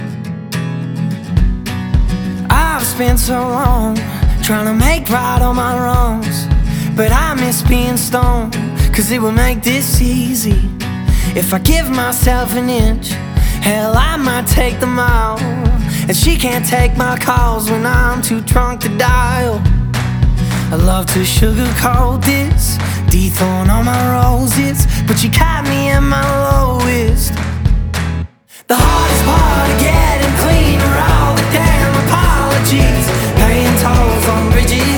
Жанр: Альтернатива / Кантри